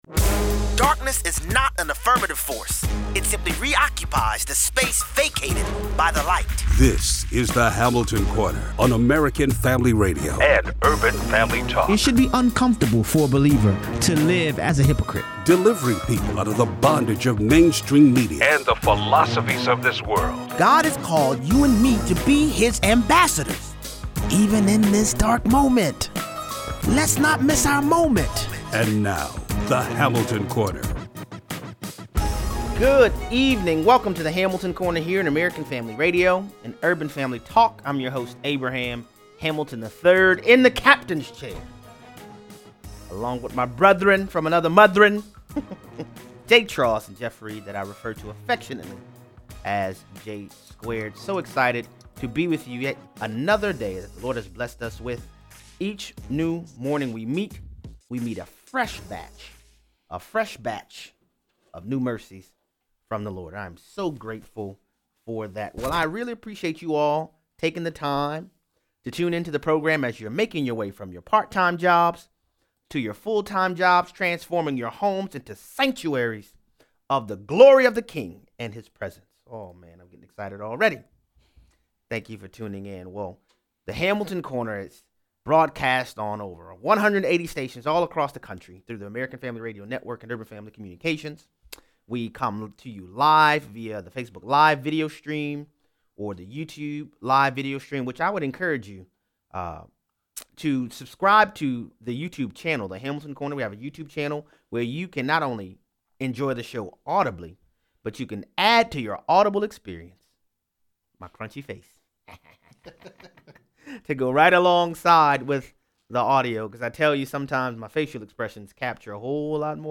Revival for eternity’s sake is the only answer. Callers weigh in.